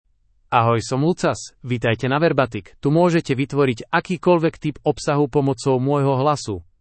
MaleSlovak (Slovakia)
LucasMale Slovak AI voice
Lucas is a male AI voice for Slovak (Slovakia).
Voice sample
Lucas delivers clear pronunciation with authentic Slovakia Slovak intonation, making your content sound professionally produced.